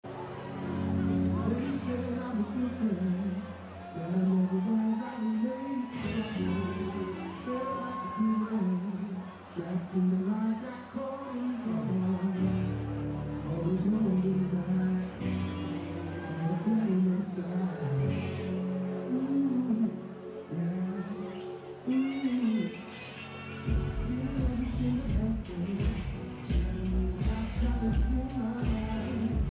When I sing this song it’s like I finally can.